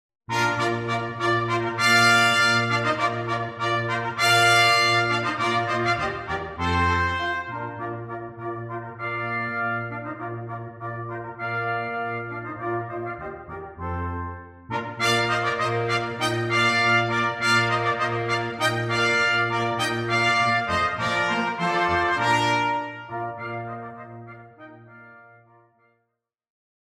Performance excerpts
Brass